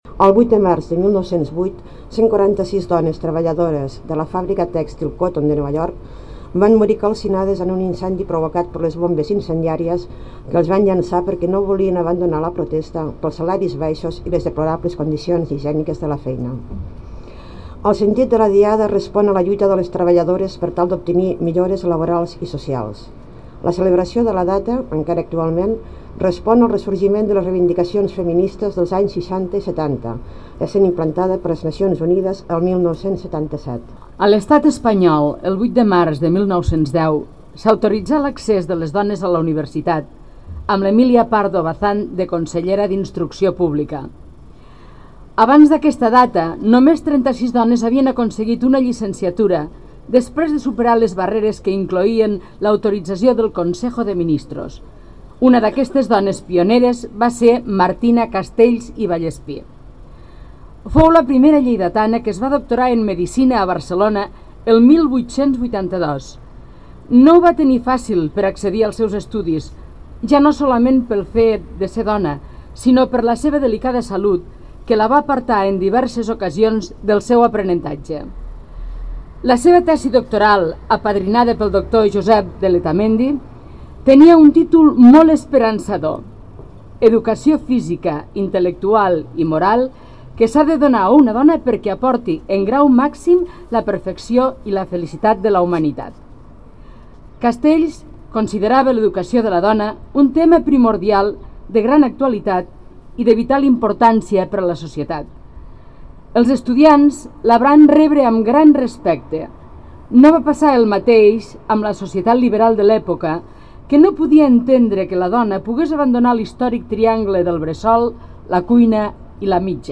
Acte unitari de commemoració del Dia Internacional de les Dones